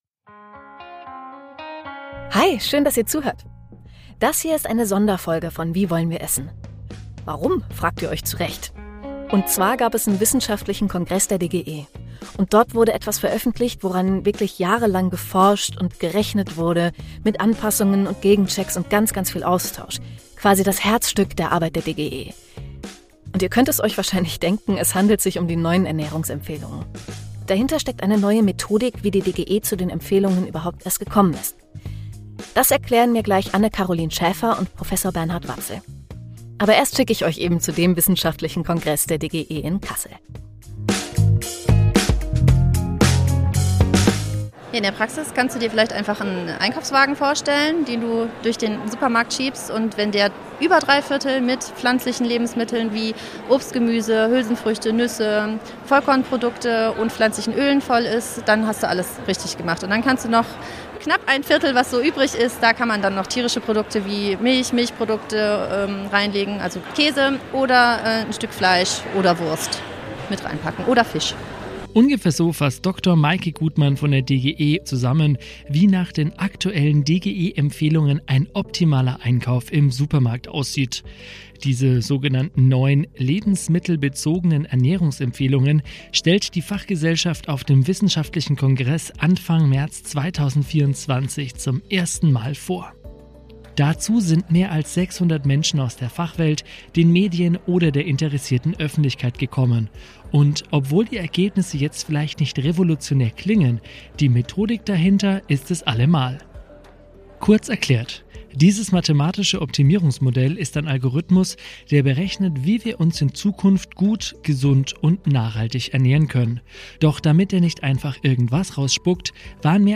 Anlässlich der Veröffentlichung der neuen lebensmittelbezogenen Ernährungsempfehlungen der DGE erscheint die Folge früher als gewohnt, quasi live vom DGE-Kongress in Kassel.